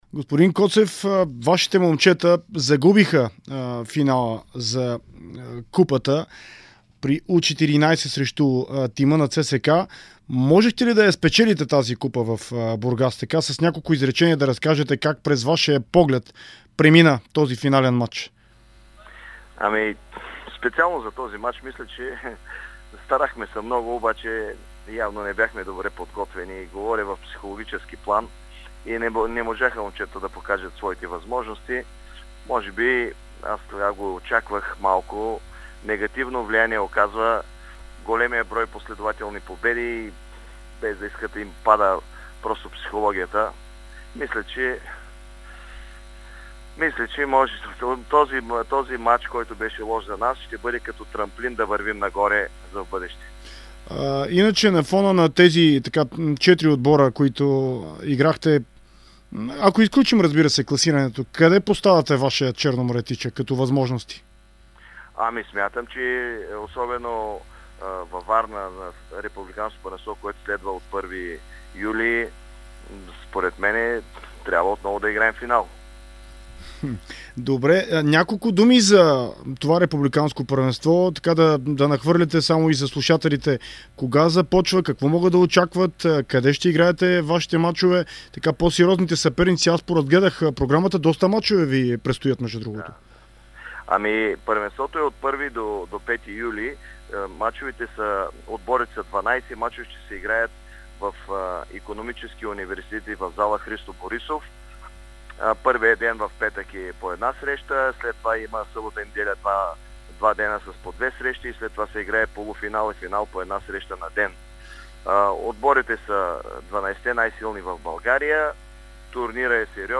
интервю за Дарик радио